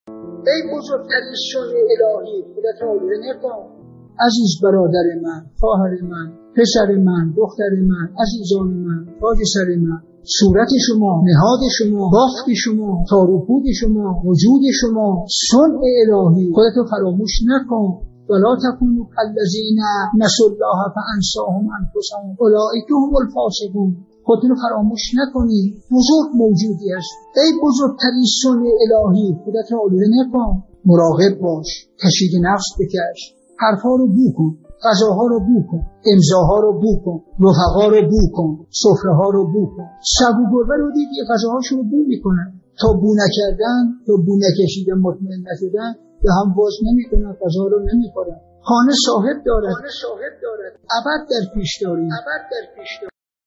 به گزارش خبرگزاری حوزه، مرحوم علامه حسن زاده آملی در یکی از سخنرانی‌های خود به موضوع «فراموشی خود، فراموشی خدا» تأکید کردند که تقدیم شما فرهیختگان می‌شود.